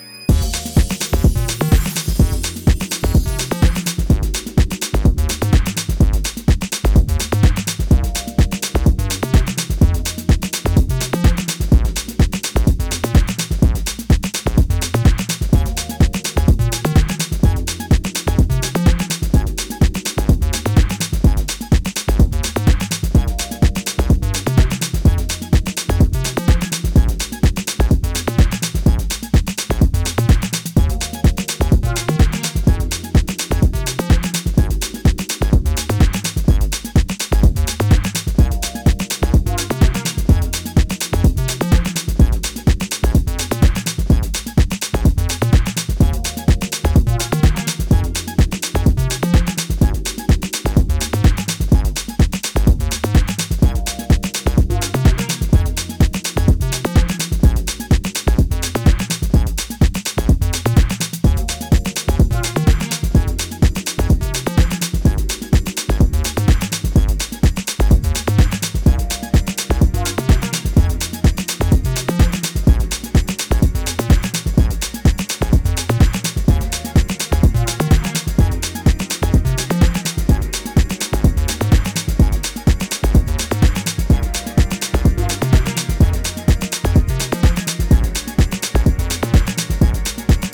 ジャンル(スタイル) JAZZ HOUSE / HOUSE